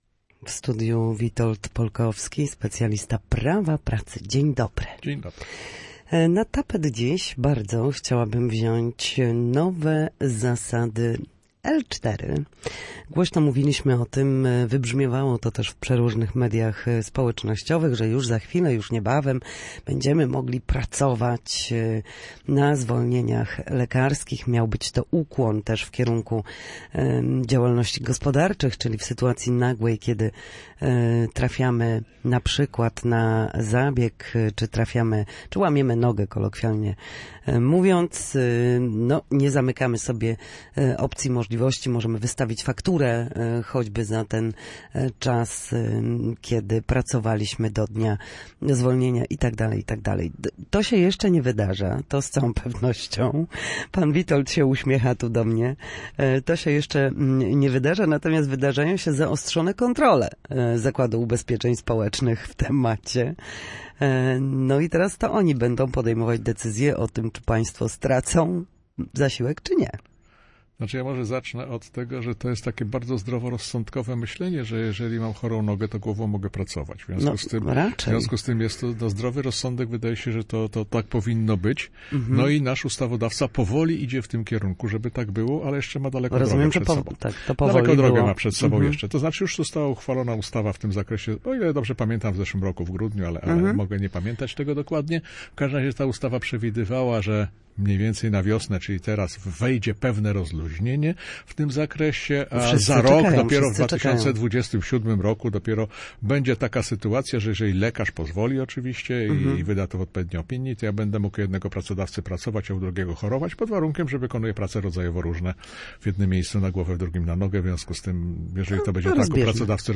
W każdy wtorek po godzinie 13:00 na antenie Studia Słupsk przybliżamy Państwu zagadnienia dotyczące prawa pracy.